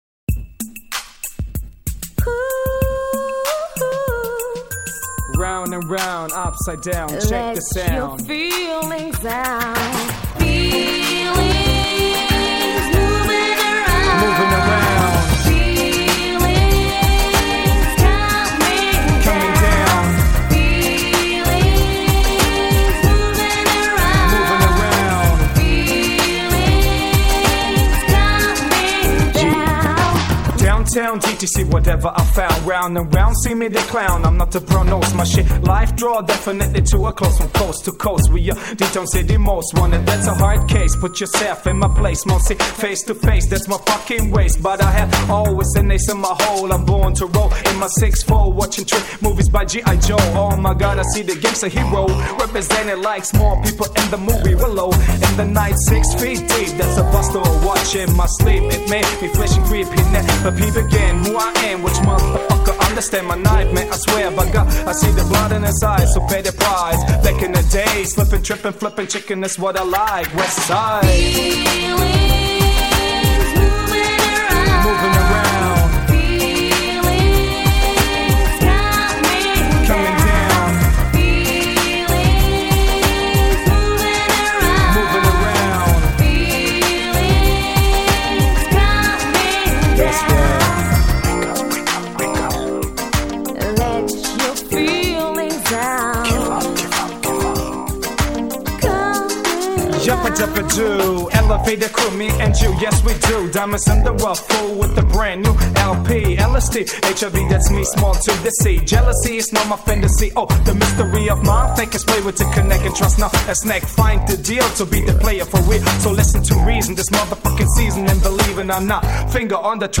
Жанр: Rap
Рэп Хип-хоп.